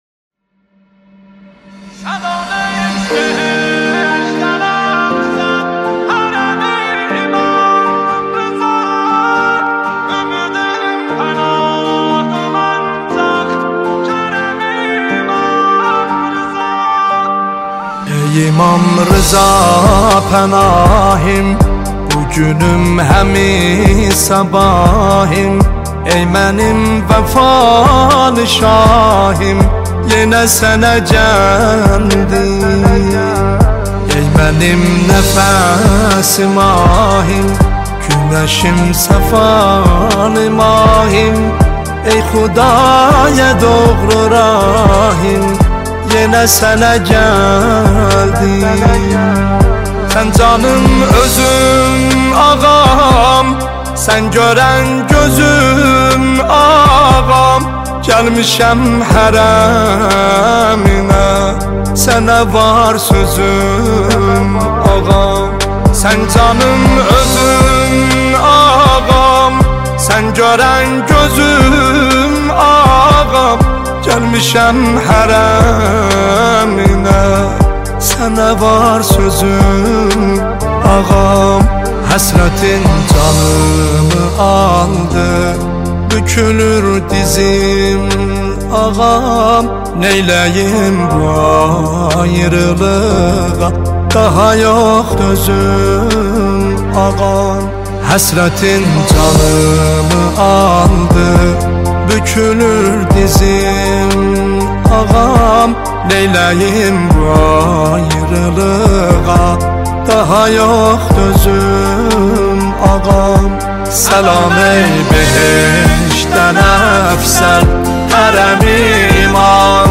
نماهنگ دلنشین ترکی
ویژه مناجات با امام رضا علیه السلام